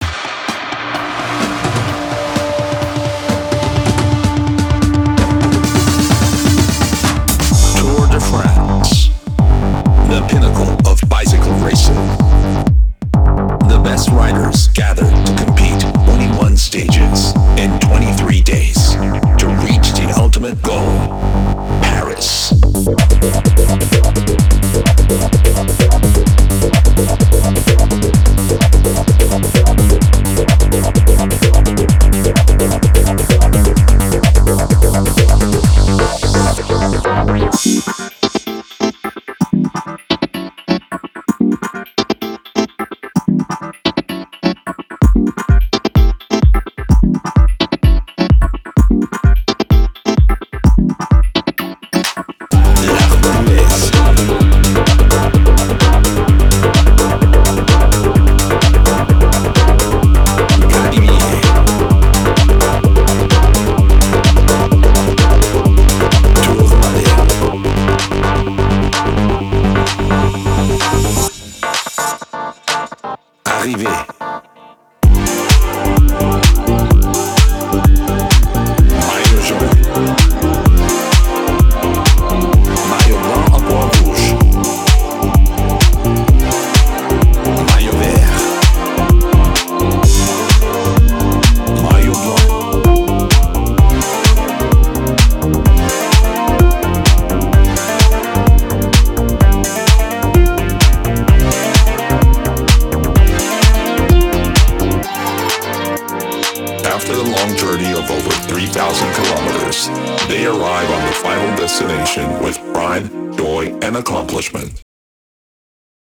BPM128
Comments[FRENCH DEEP HOUSE]